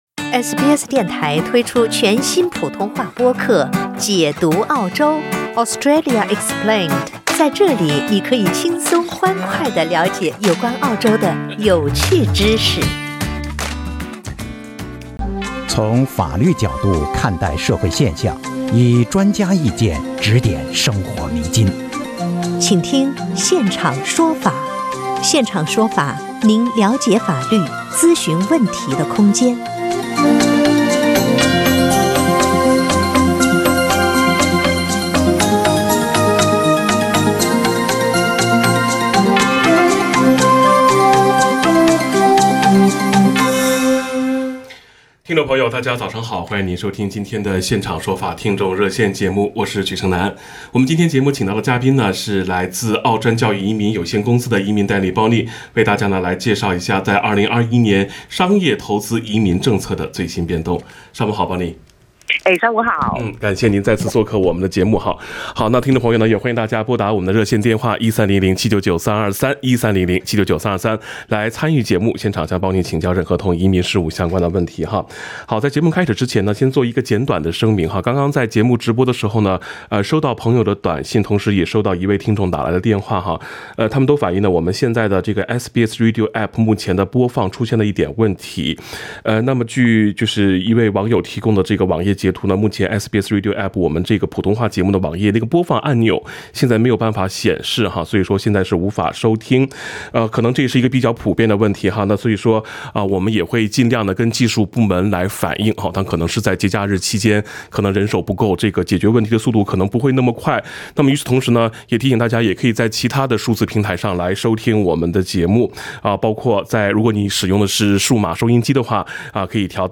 听众热线